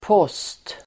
Uttal: [pås:t]